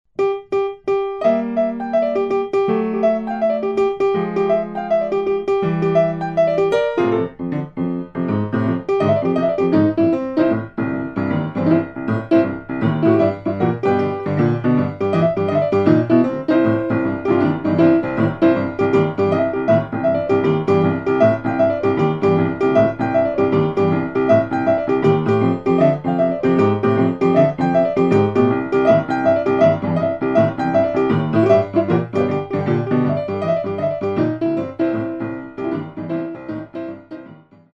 Style: Boogie Woogie Piano